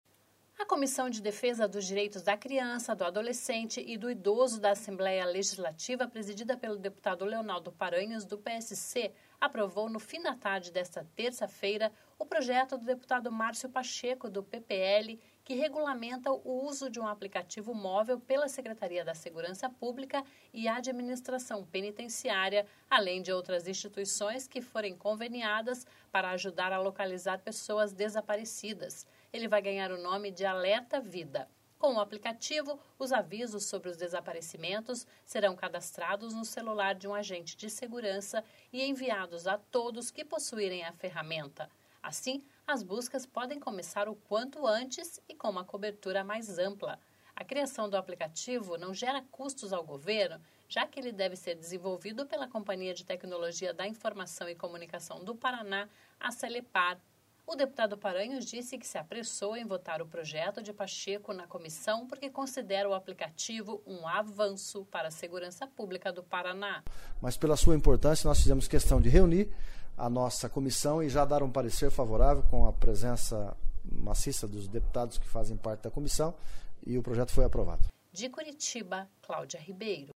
(sonora)